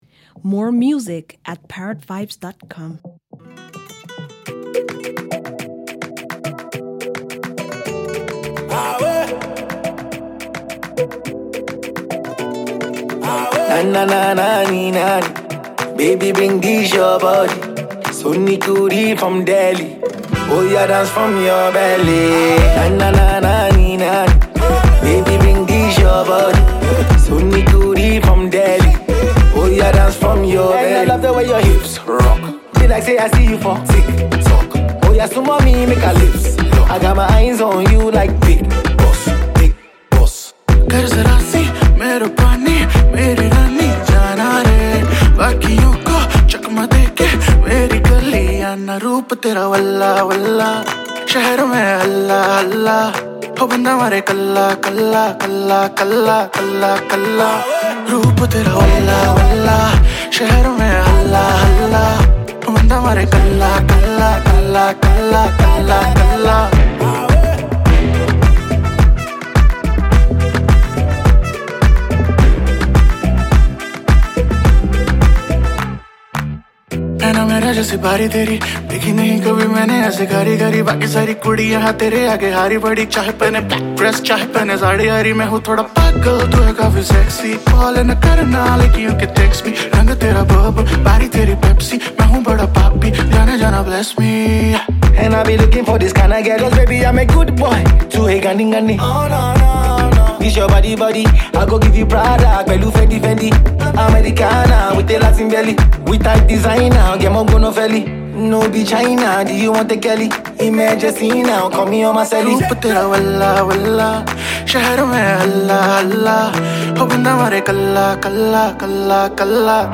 Famous Indian rapper, singer